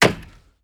Chopping wood 5.wav